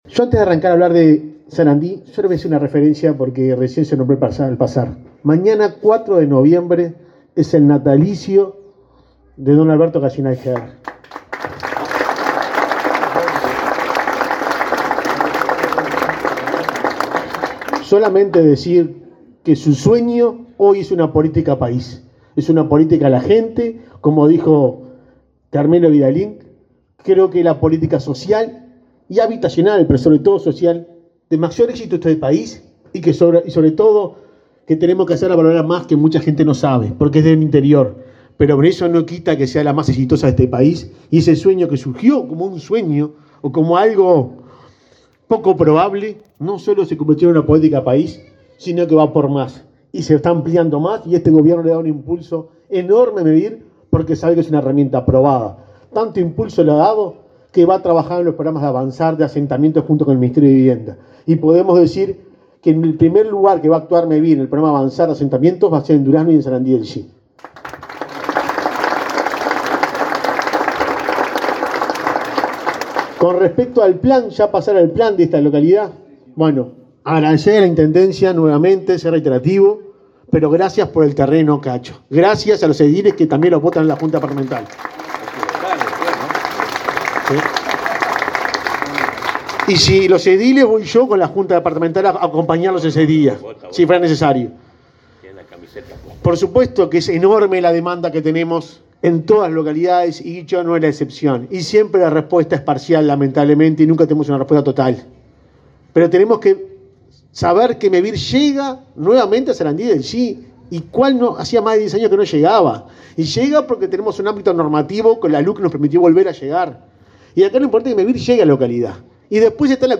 Palabra de autoridades en Durazno
Palabra de autoridades en Durazno 03/11/2022 Compartir Facebook X Copiar enlace WhatsApp LinkedIn Representantes del Ministerio de Vivienda, Mevir y la Intendencia de Durazno realizaron anuncios sobre soluciones habitacionales para la localidad de Sarandí del Yí. El presidente de Mevir, Juan Pablo Delgado, y el subsecretario de Vivienda, Tabaré Hackembruch, hablaron sobre la importancia de la temática.